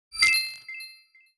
Shimmery Reward 5.wav